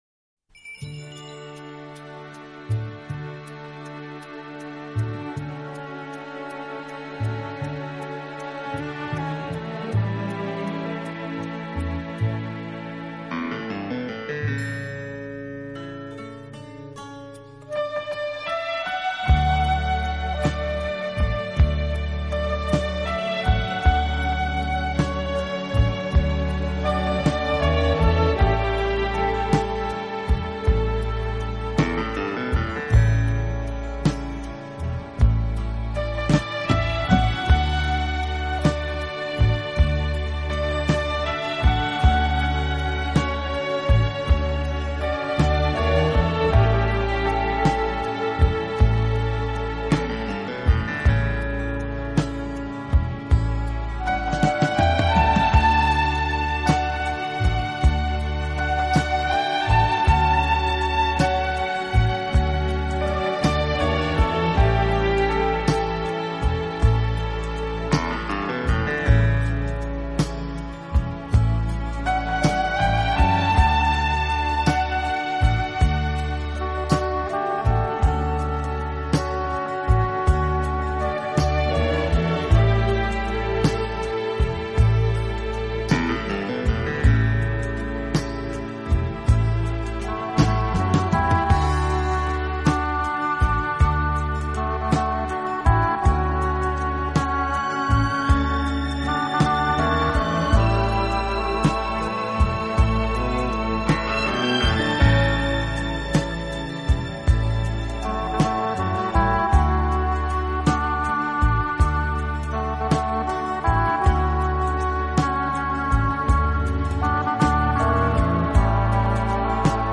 大多轻拉轻弹轻敲，给人安宁、清静、舒适的感觉。
是乐队演奏的主要乐器，配以轻盈的打击乐，使浪漫气息更加浓厚。